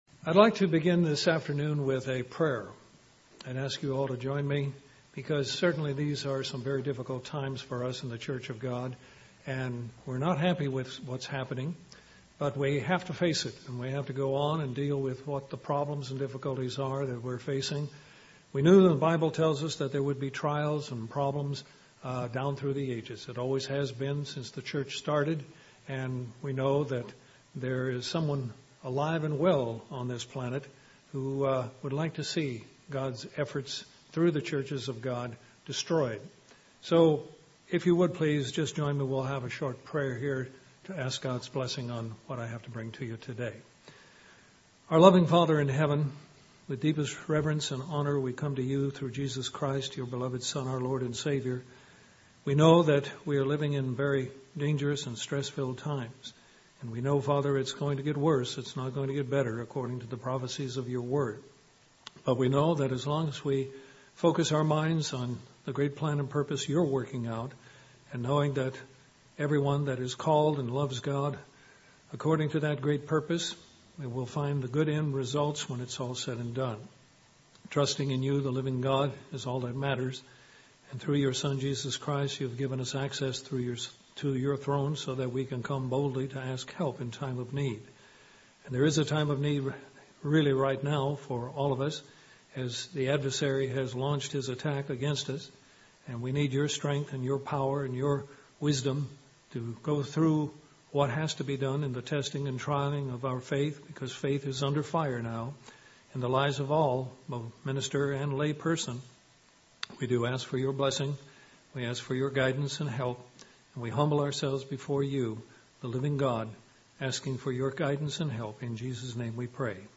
This sermon urges us to make sure we are grounded on the Rock, Jesus Christ.